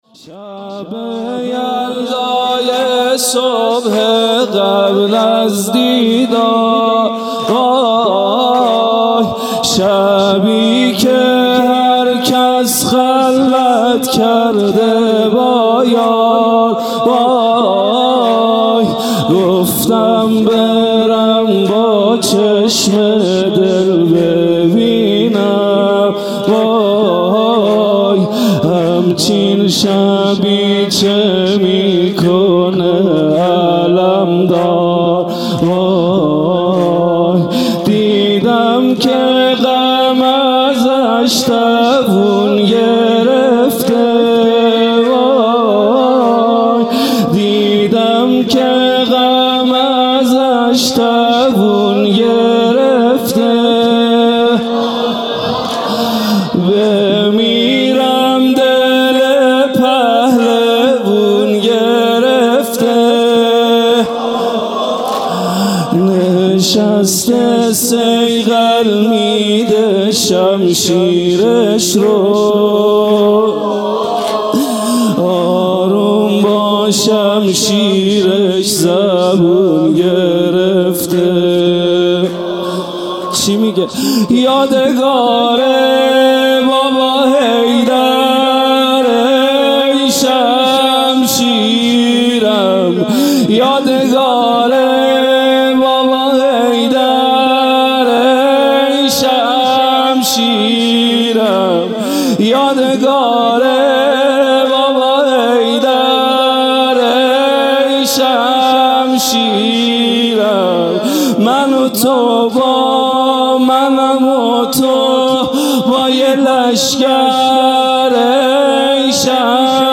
خیمه گاه - هیئت بچه های فاطمه (س) - زمینه | شب یلدای صبح قبل از دیدار
محرم 1441 | شب دهم